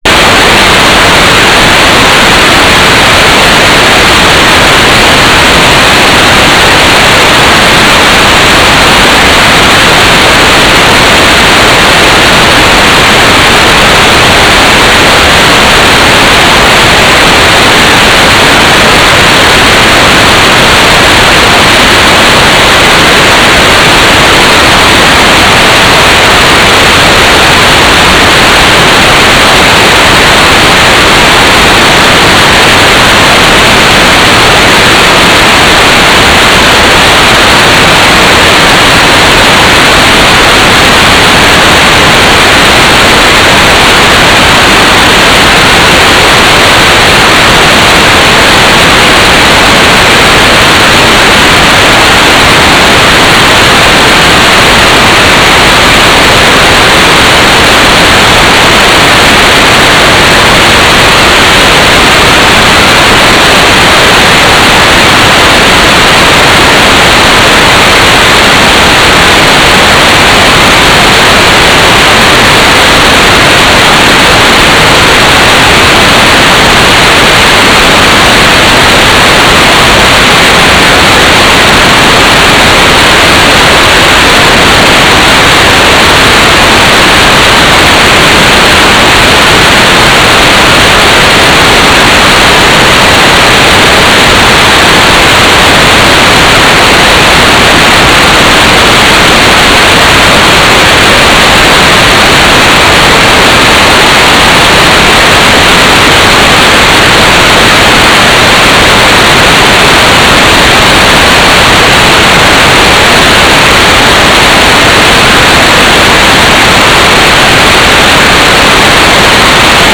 "waterfall_status": "without-signal",
"transmitter_description": "Mode U - GMSK4k8",